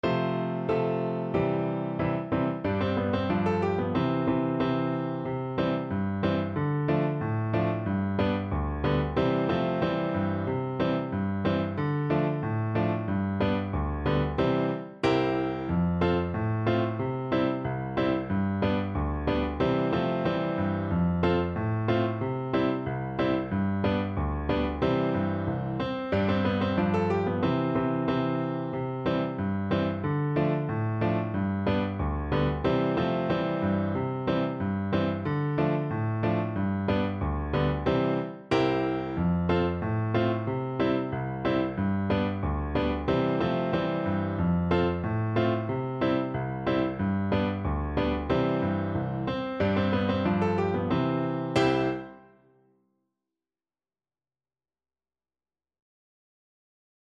Jolly =c.92
2/2 (View more 2/2 Music)
Swiss